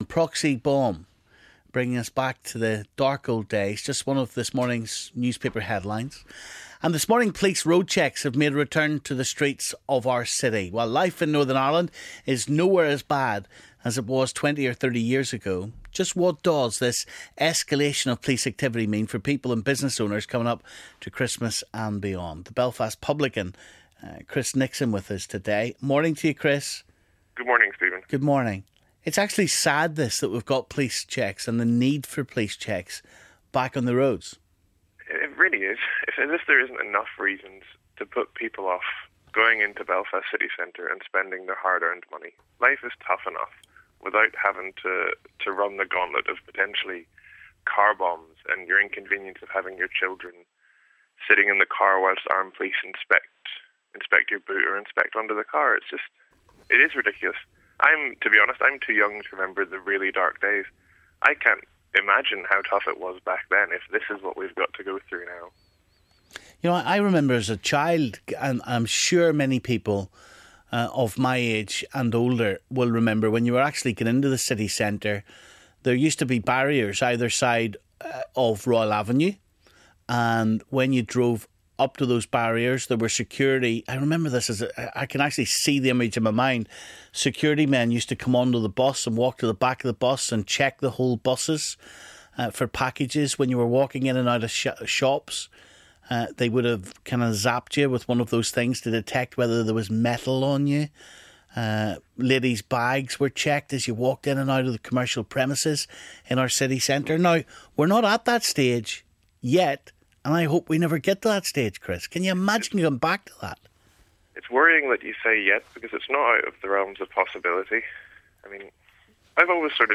City centre bombing & police road checks - Belfast trader voices concern